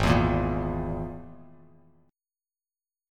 G#sus2#5 chord